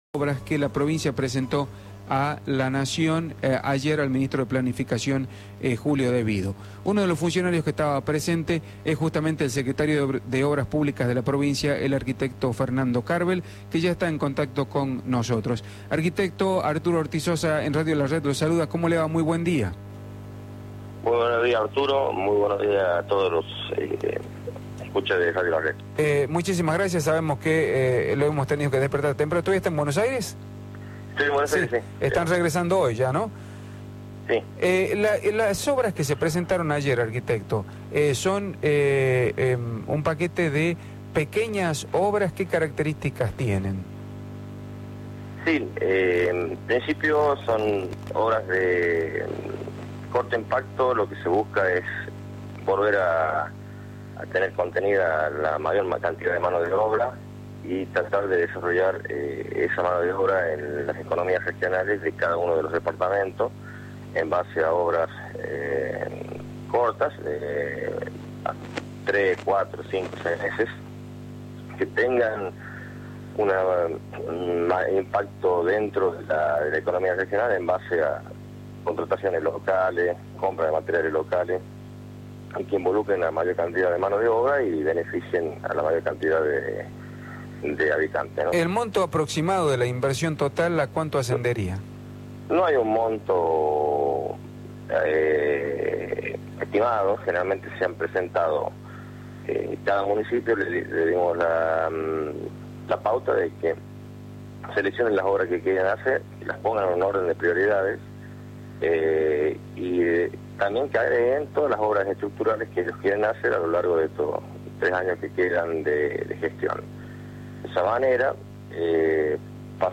Fernando Cárbel, secretario de Obras Públicas, por Radio La Red
En la mañana del jueves, el secretario de Obras Públicas, Fernando Cárbel, reafirmó por Radio La Red la iniciativa de la construcción del acueducto del río Blanco.